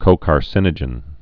(kōkär-sĭnə-jən, kō-kärsĭn-ə-jĕn)